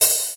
Dusty Open Hat 03.wav